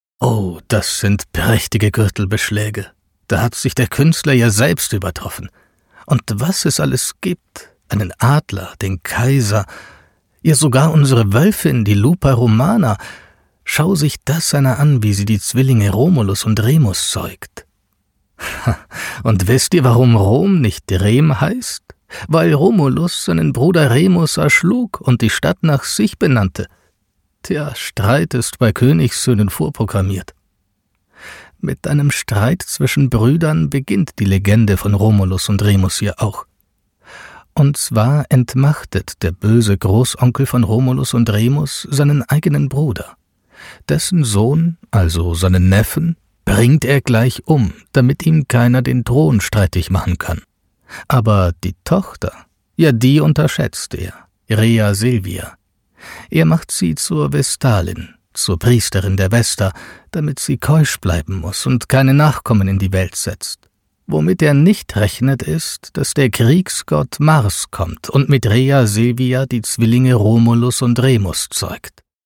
Natural, Versátil, Cool, Seguro, Empresarial
Audioguía